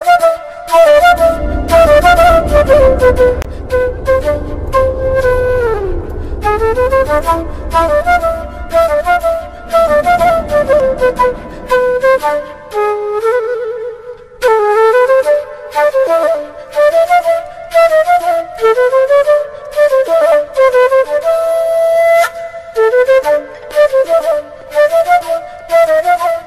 Flute Ringtones Instrumental Ringtones